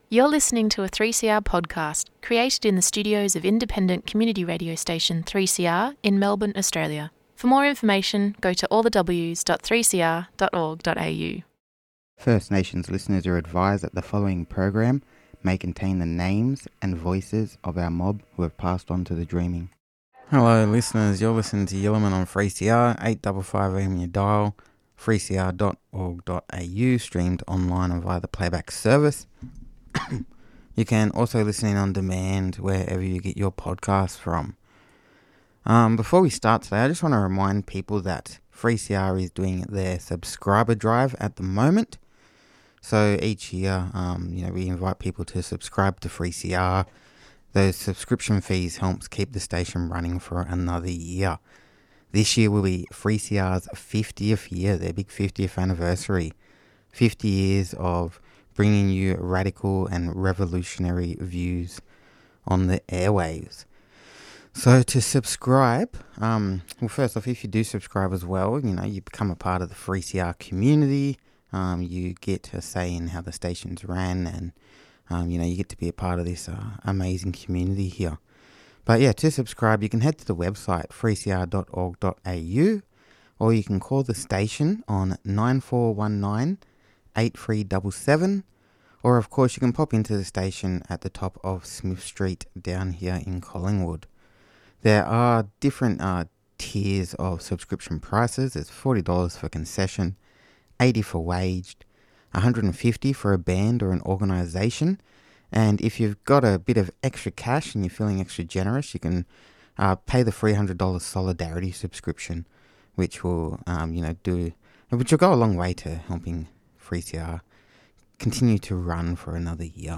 interviews from the blak sovereign movement conference